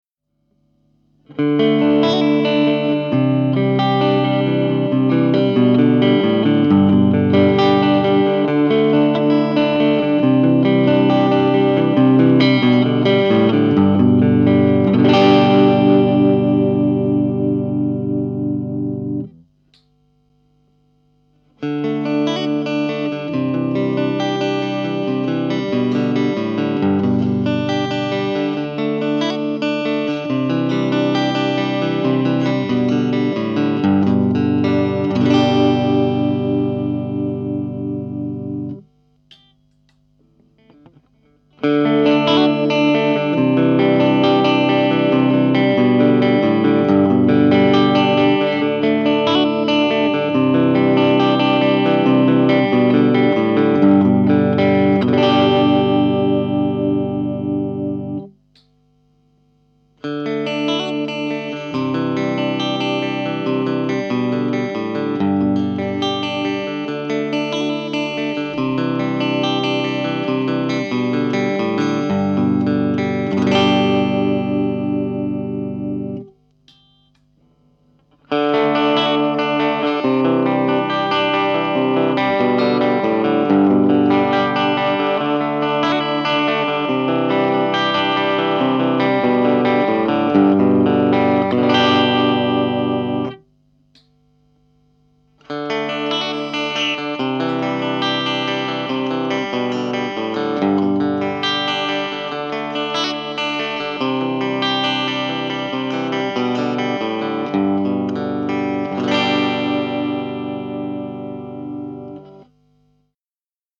Звук гитары вполне приличный, хамбакеры легко раскачивают эффекты перегруза, чистый звук не лишен ВЧ.
1. Чистый звук 2,74 Мб
От нека до бриджа с отключением половинок хамбакеров
clean.mp3